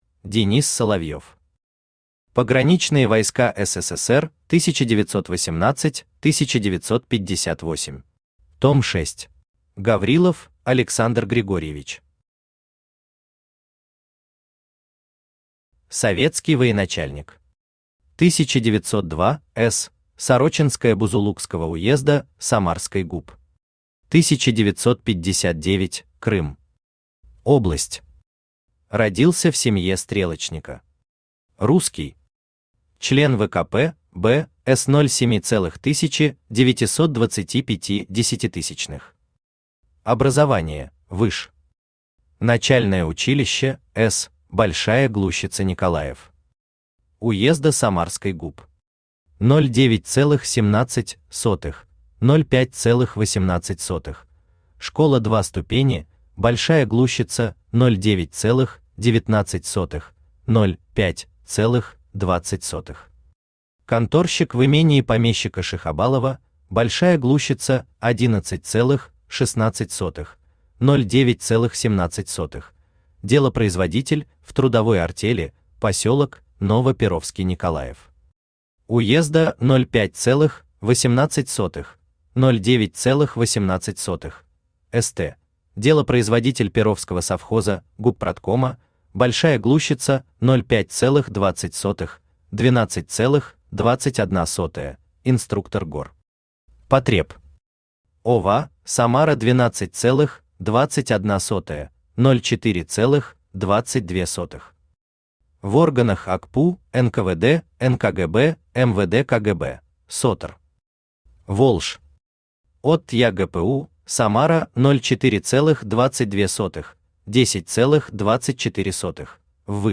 Aудиокнига Пограничные войска СССР 1918-1958. Том 6 Автор Денис Соловьев Читает аудиокнигу Авточтец ЛитРес.